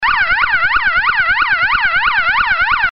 Serie: SIRENAS ELECTRÓNICAS
Elevada potencia acústica - 115dB